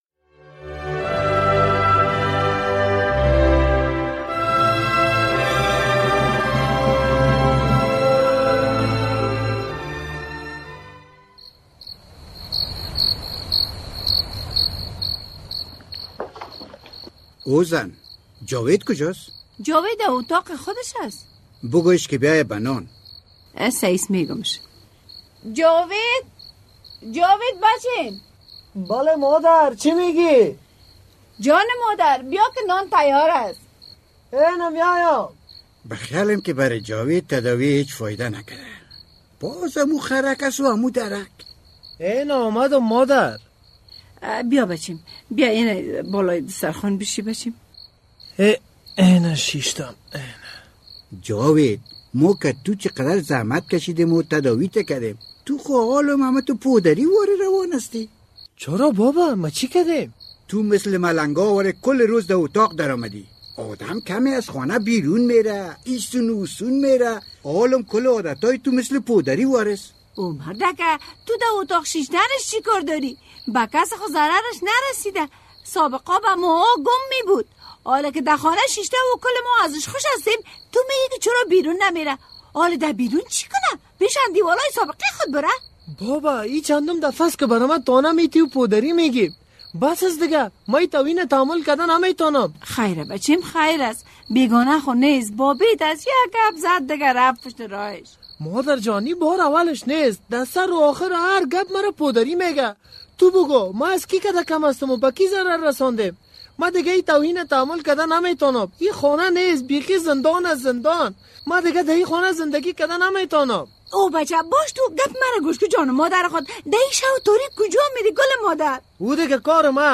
درامه: چرا جاوید بعد از ترک اعتیاد خانه را ترک کرد؟